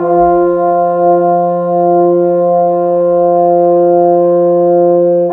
Rock-Pop 22 Horns 01.wav